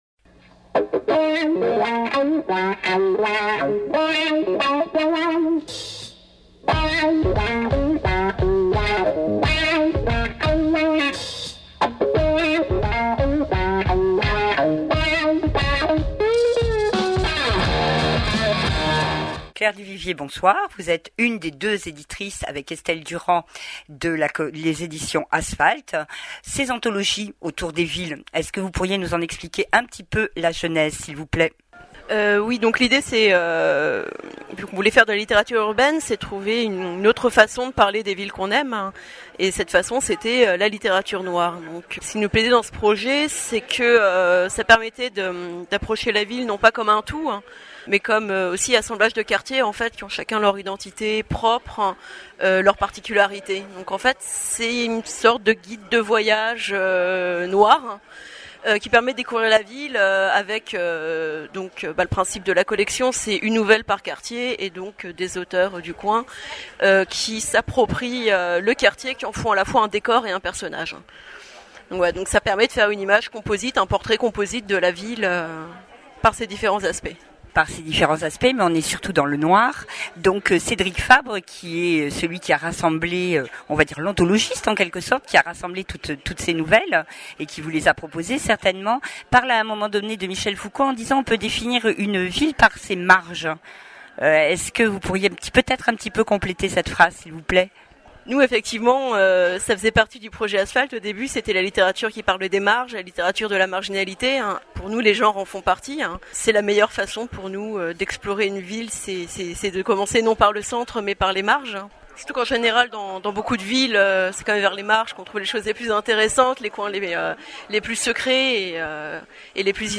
entretien avec Didier Daeninckx